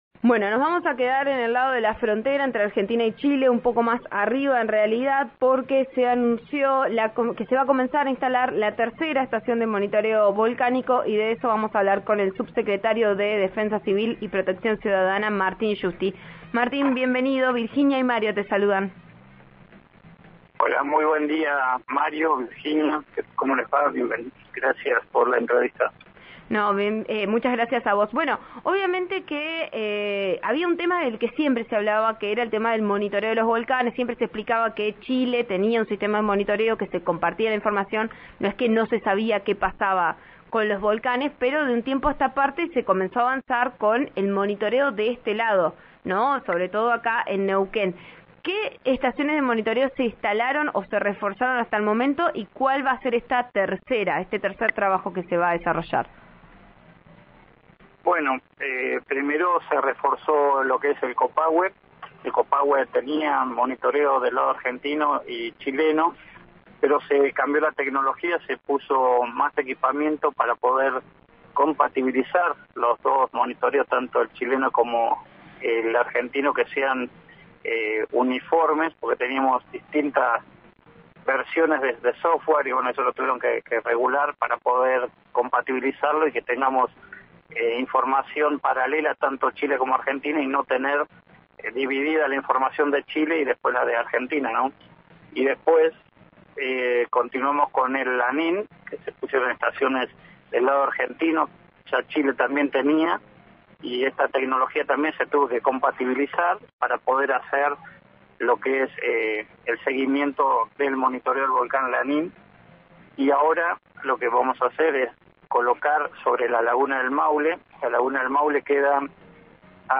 El subsecretario de Defensa Civil y Protección Ciudadana, Martín Giusti, confió en declaraciones al programa Vos a Diario de RN RADIO (89.3) que se trata de un equipamiento para controlar el complejo volcánico y, sobre todo, la laguna que crece año a año.